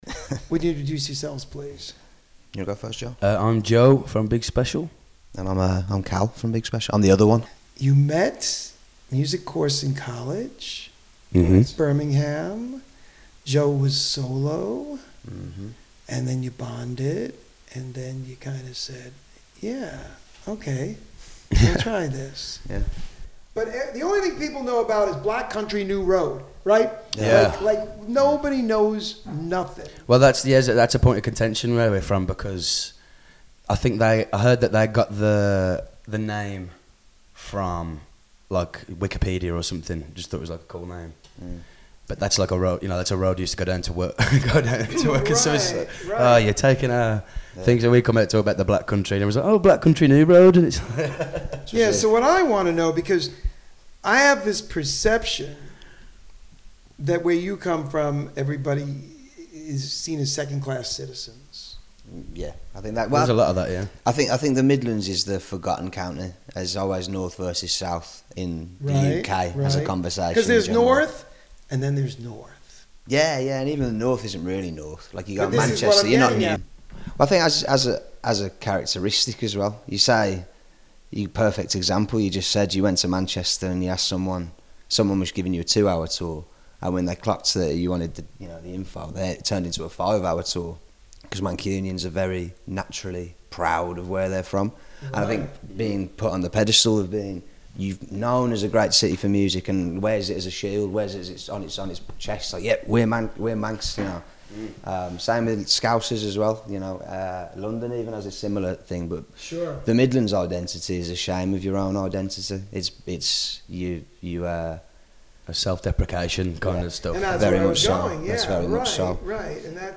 Also, be sure to listen for our exclusive “Musical Sandwich” featuring two of the interviewed artist’s songs as the bread and as many musical fillings as they can muster up in-between! This Week's Interview (04/27/2025): Big Special